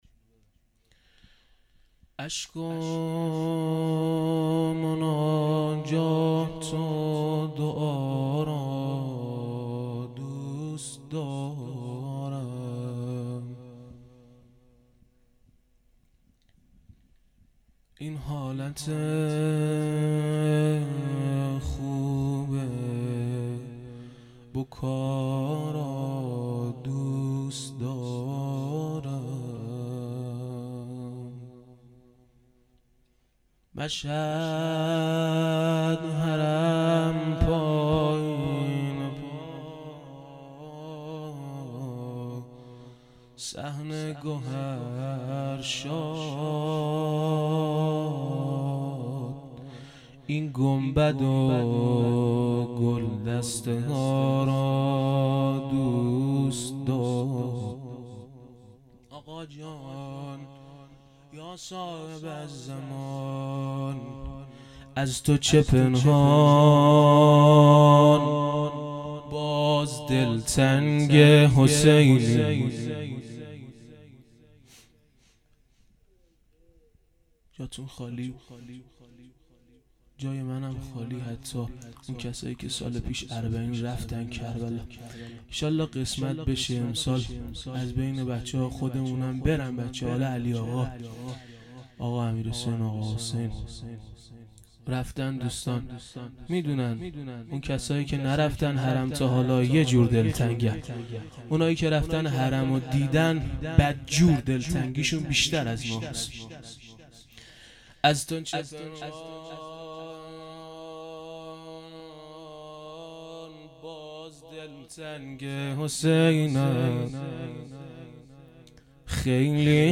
مسجد امام موسی بن جعفر علیه السلام